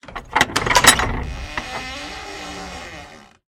dooropen1.ogg